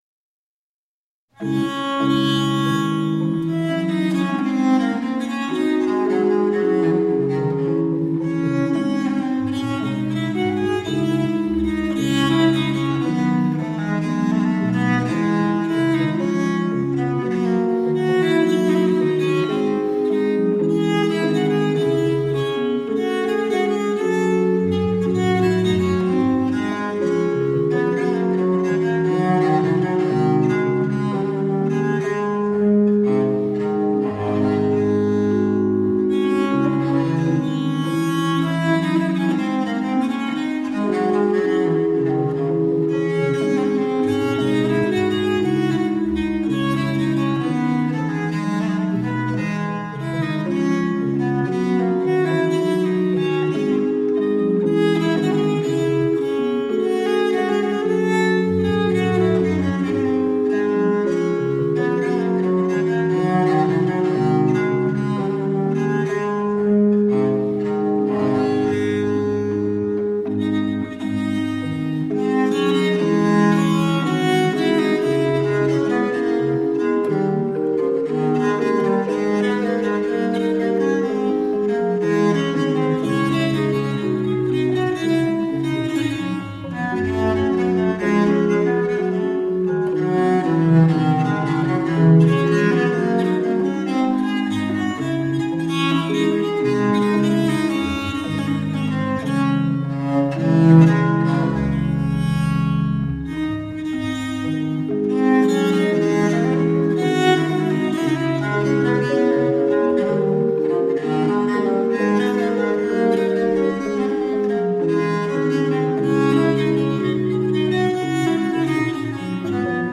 German Sonatas for Viola da Gamba from the Baroque period.